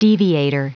Prononciation du mot : deviator
deviator.wav